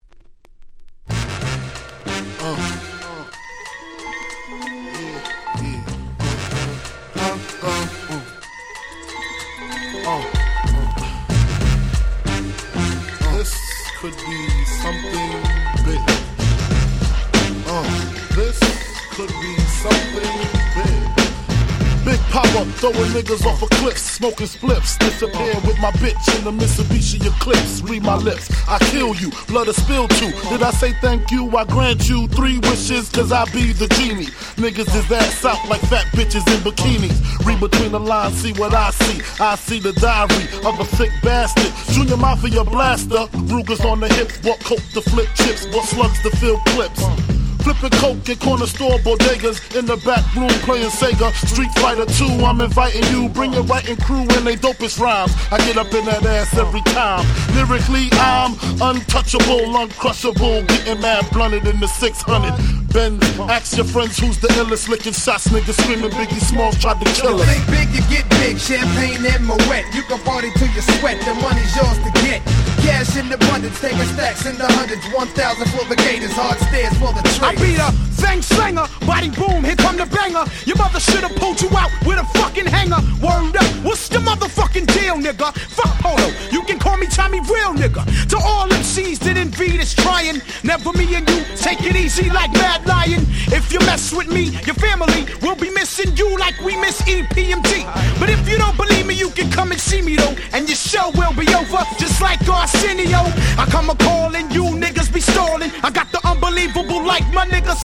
95' Super Nice 90's Hip Hop !!